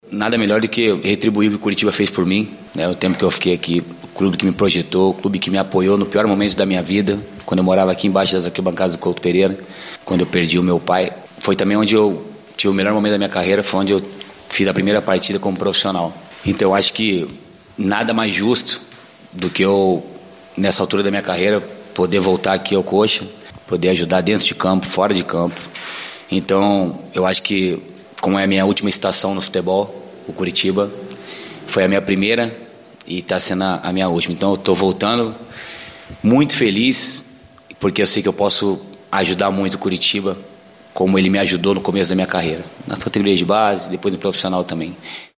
De volta ao Coritiba após 20 anos, Rafinha concedeu uma entrevista coletiva nesta terça-feira (14) na sala de imprensa do Estádio Couto Pereira.